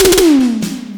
FILLTOMEL1-L.wav